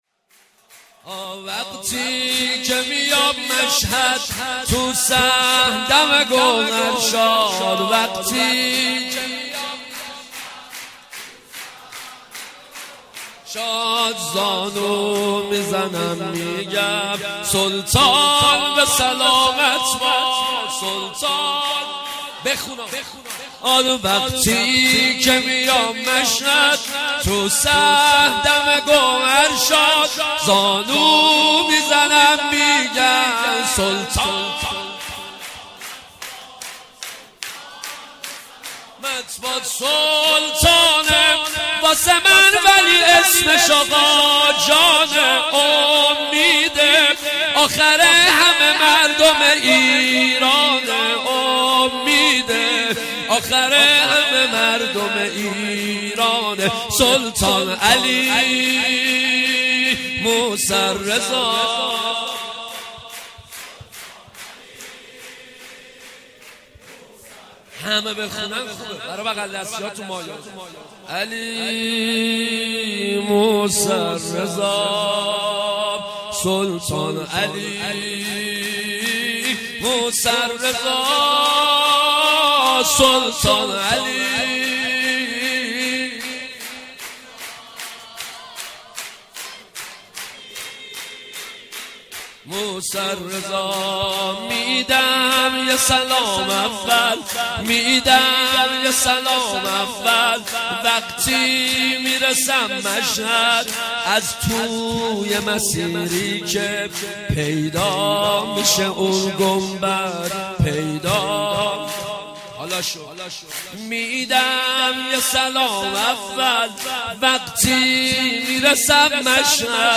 ولادت امام رضا(ع)